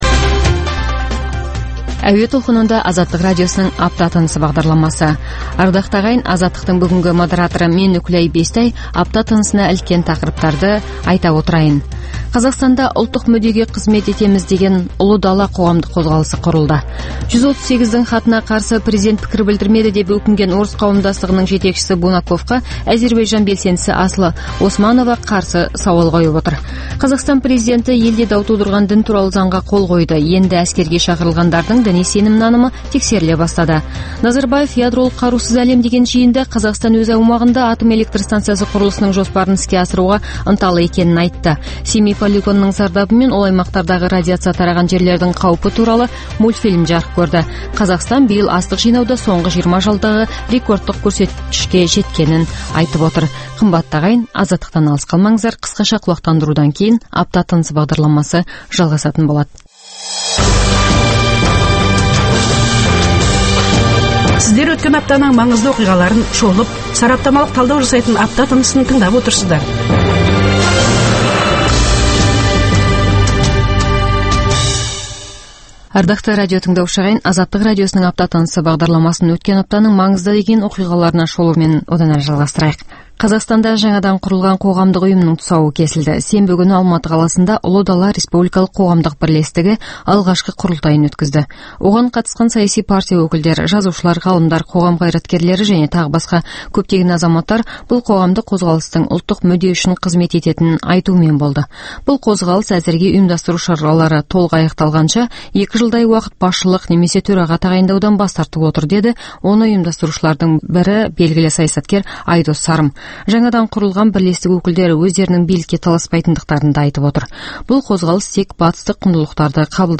Апта тынысы – Апта бойына орын алған маңызды оқиға, жаңалықтарға құрылған апталық шолу хабары.